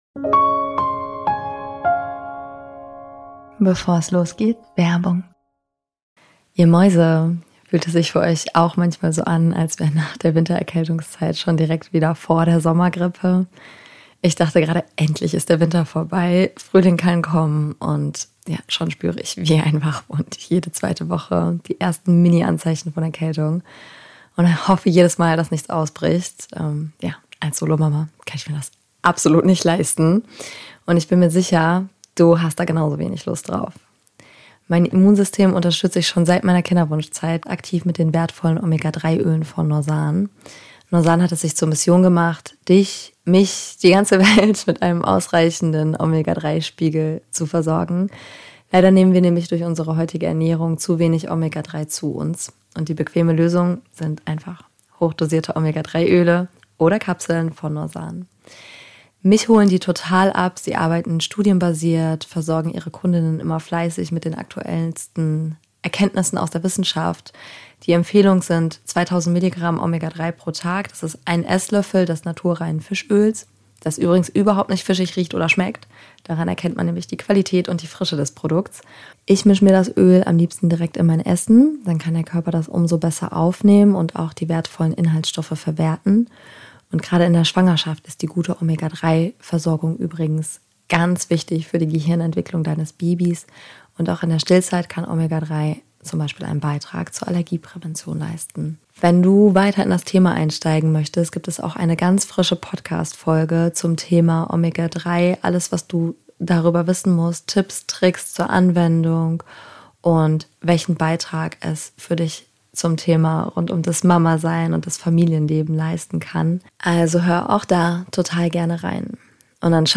Heute erwartet dich mal wieder ein Interview Gast im Podcast.